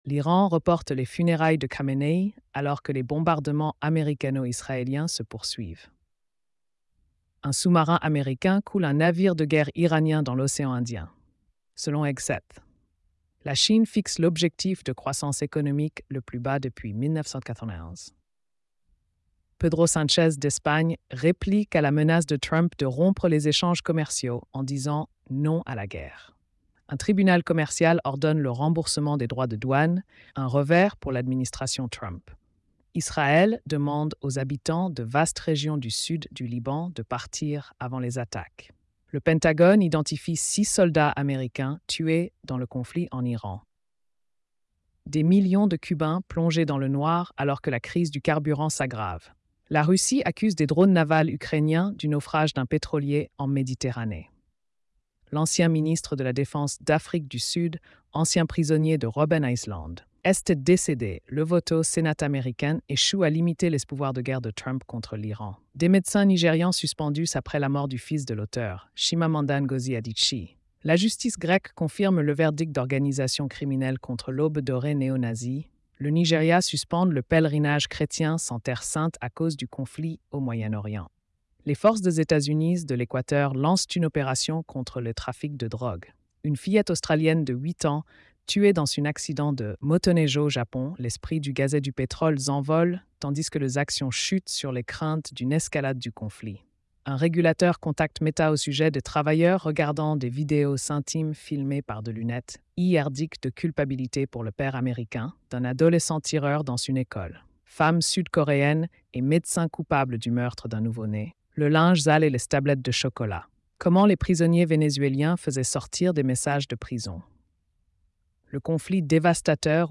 🎧 Résumé des nouvelles quotidiennes. |